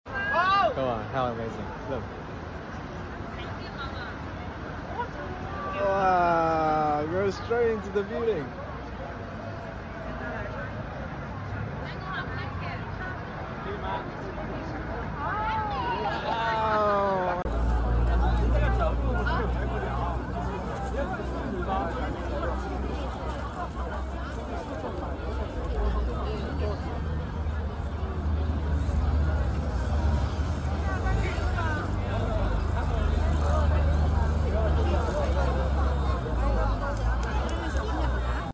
It's at Liziba station line 2 in Chongqing in case you want to know...